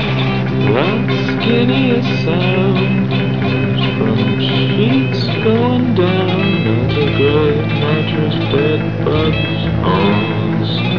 odd vocal with an odd lyric about the "skinniest sound."